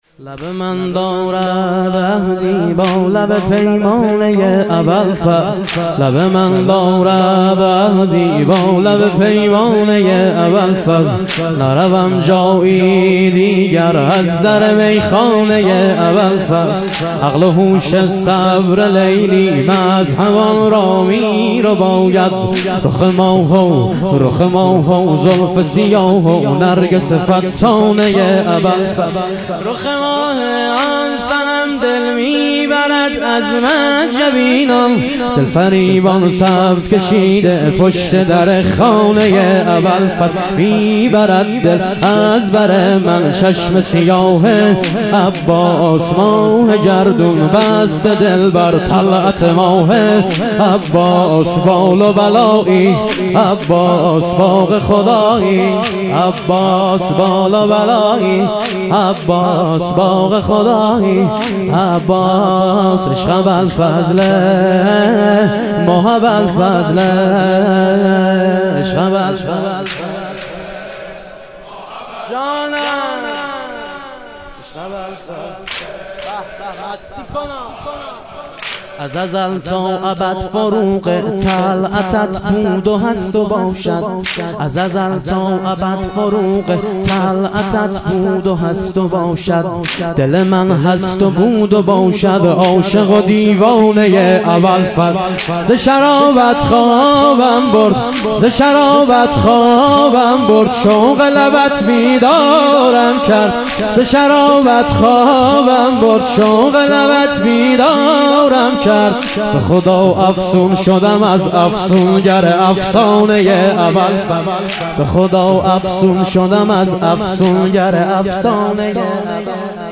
ظهور وجود مقدس امام حسن عسکری علیه السلام - واحد